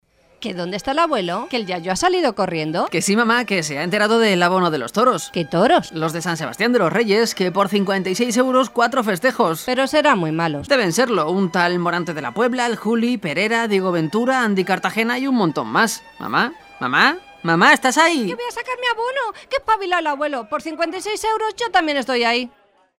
Durante los últimos días se está escuchando en las radios de la comunidad de Madrid una original campaña para la venta de abonos para la feria en honor al Santísimo Cristo de los Remedios de San Sebastián de los Reyes.
anuncios_toros_sanse.mp3